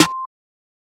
SNARE 25.wav